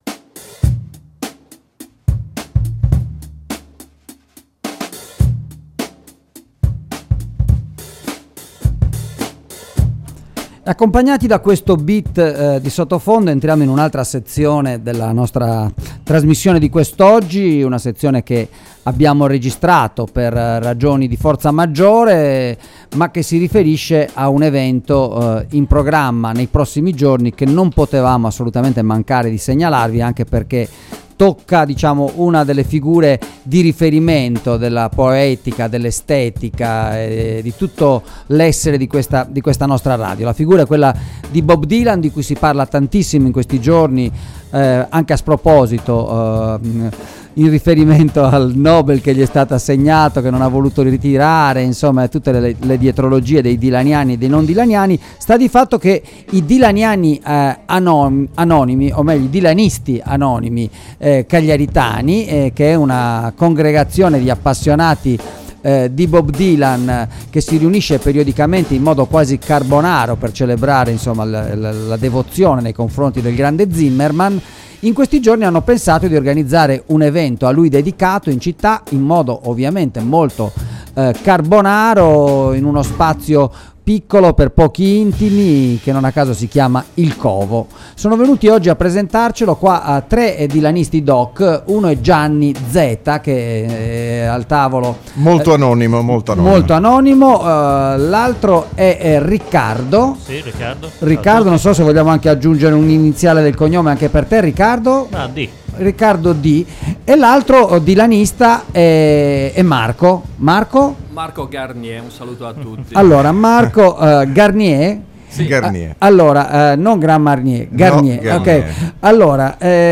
Riunione dei Dylanisti Anonimi ASCOLTA L’INTERVISTA http